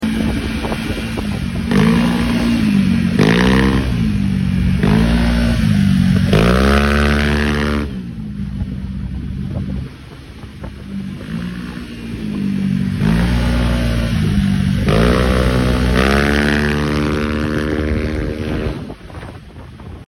suara adem gerung ramah lingkungan rekomen banget ini knalpot🥰